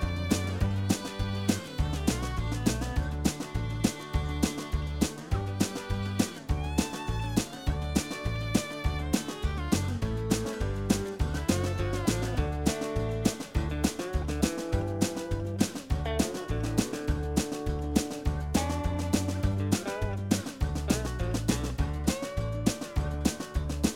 Minus All Acoustic Guitars Country (Female) 3:39 Buy £1.50